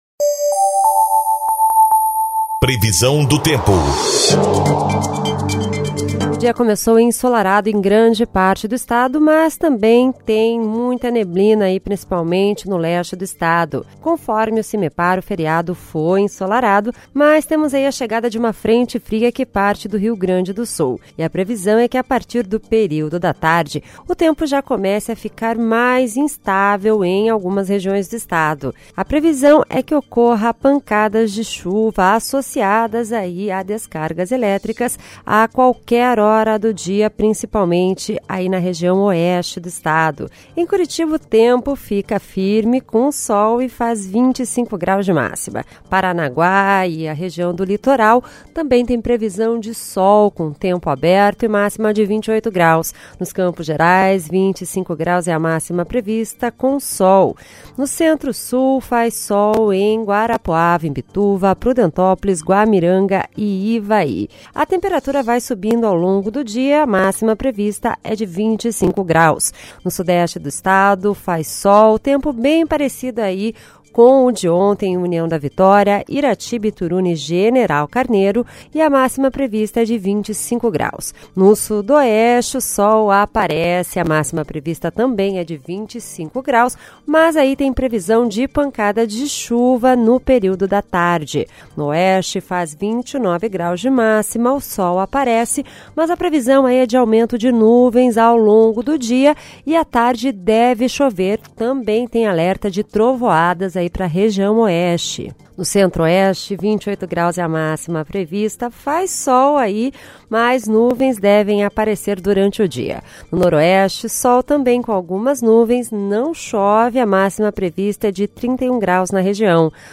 Previsão do Tempo (02/05)